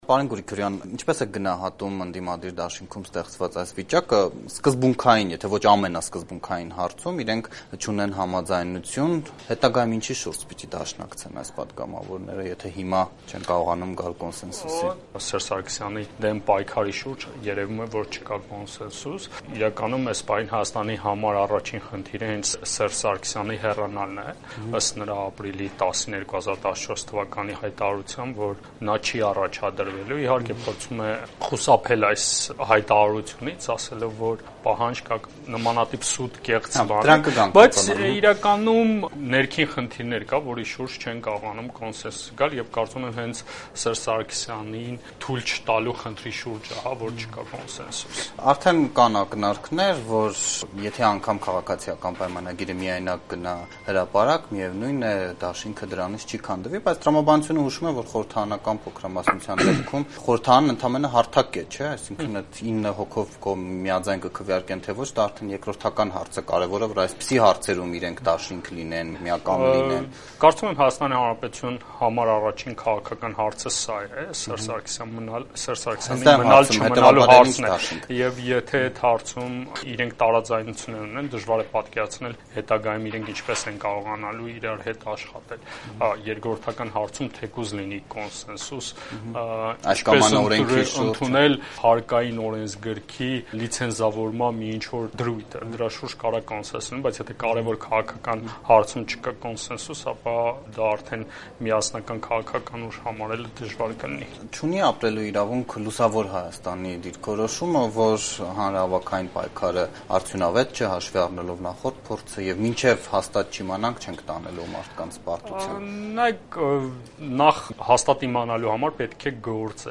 «Հայաստանում քաղաքական առաջին խնդիրը Սերժ Սարգսյանի մնալ-չմնալու հարցն է»․ քաղաքագետ
Ռեպորտաժներ